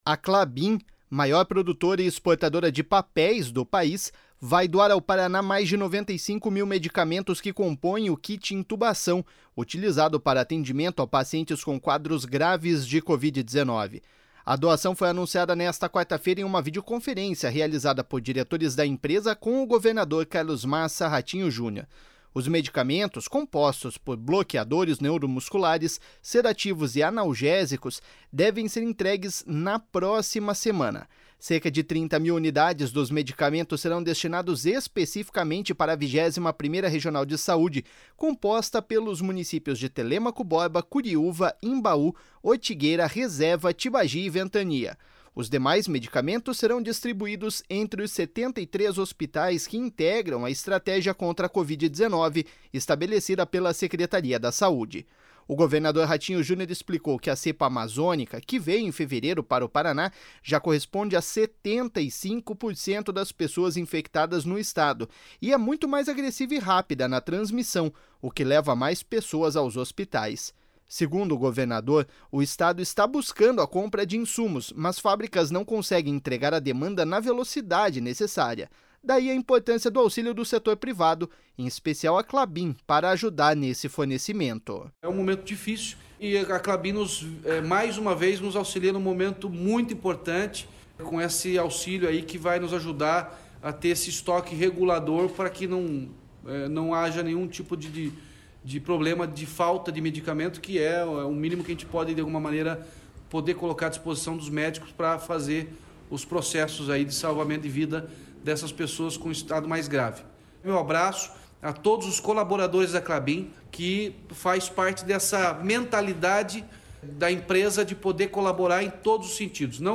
Daí a importância do auxílio do setor privado, em especial a Klabin, para ajudar com esse fornecimento.// SONORA RATINHO JUNIOR.//
O secretário estadual da saúde, Beto Preto, relatou que a doação da Klabin neste momento da pandemia foi fundamental.// SONORA BETO PRETO.//